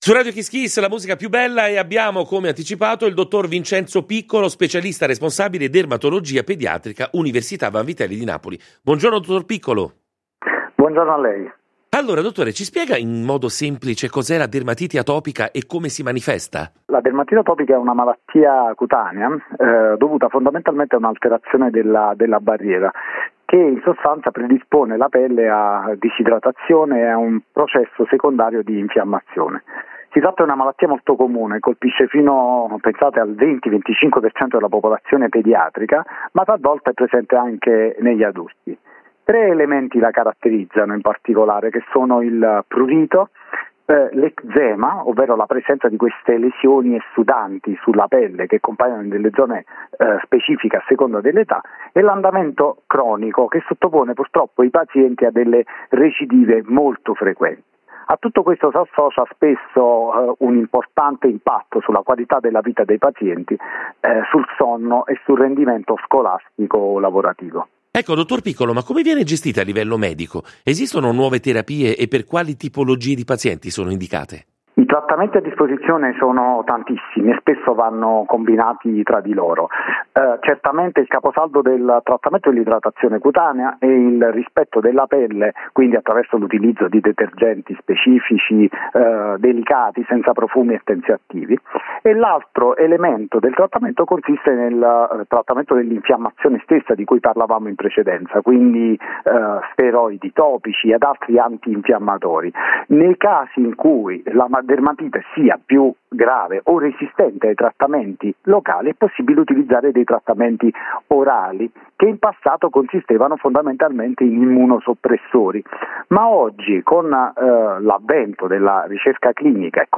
Contributo realizzato da Radio KissKiss in collaborazione con PreSa, con il supporto di Sanofi